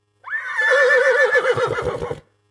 horse_neigh1.ogg